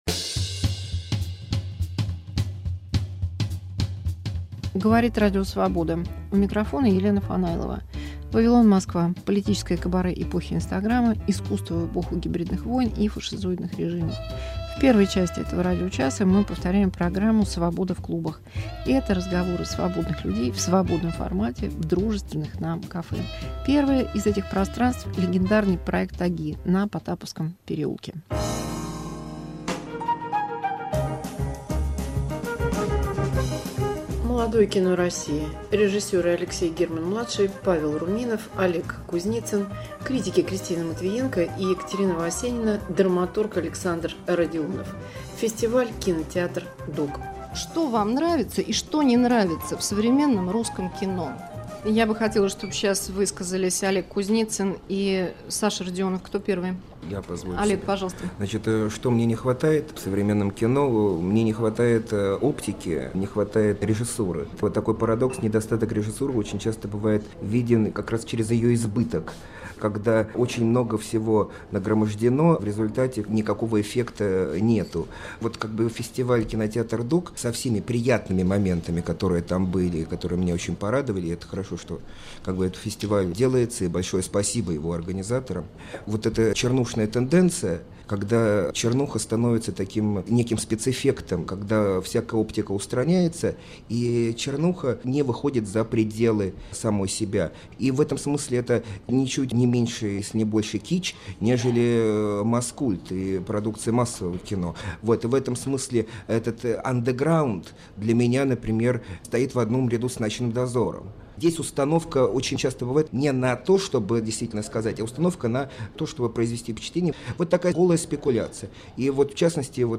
Разговор с участием Алексея Германа-мл., Павла Руминова и критиками после фестиваля КиноТЕАТР.DOC. Архив "Свободы в клубах" 2005